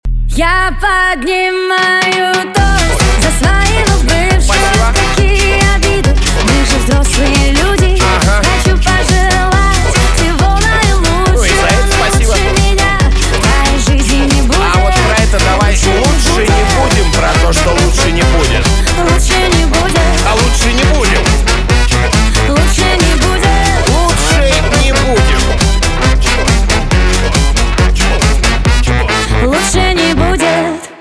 русский рок
труба
гитара